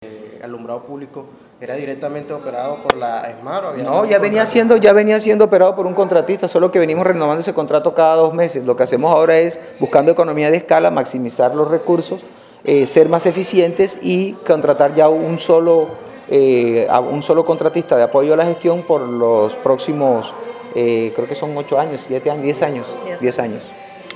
Rafael Martínez, alcalde de Santa Marta, se refiere al alumbrado público.
Declaraciones de Rafael Martínez sobre el contrato de la Essmar.
rafael_martinez_2.mp3